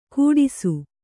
♪ kūḍisu